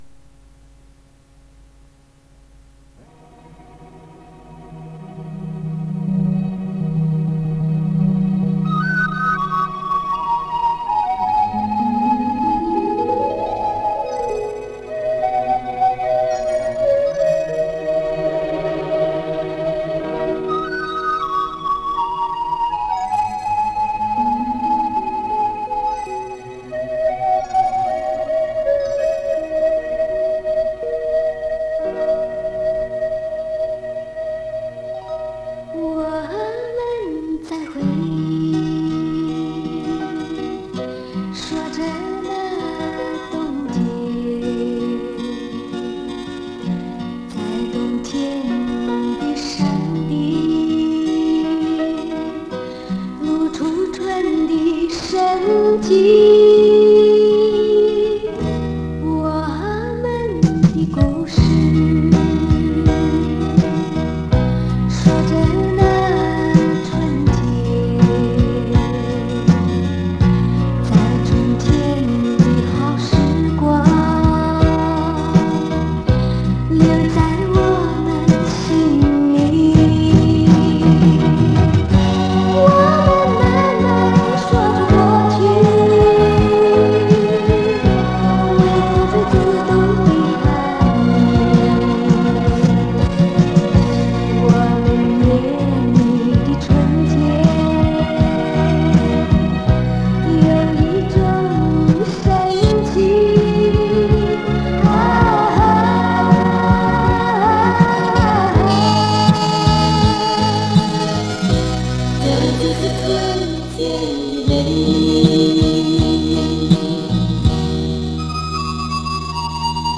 磁带数字化：2024-03-10